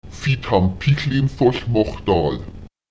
Die Betonung des Prädikats fällt normalerweise auf das Verb selbst.